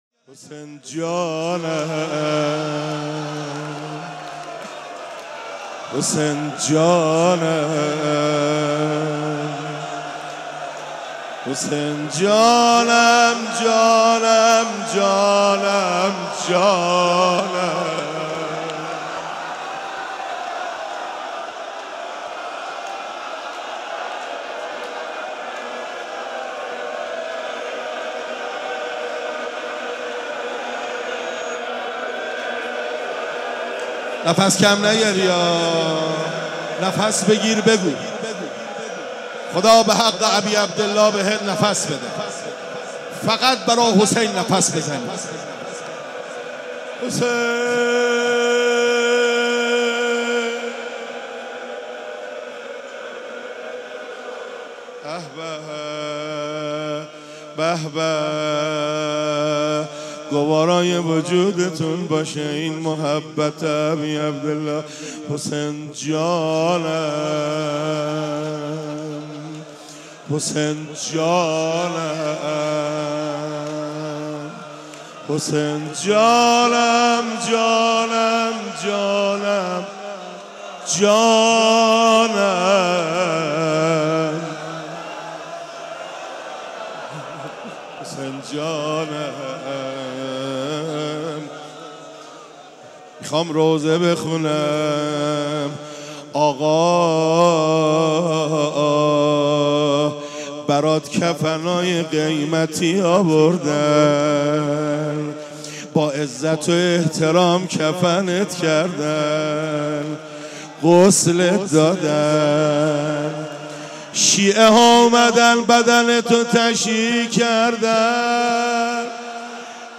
روضه امام حسین ع.mp3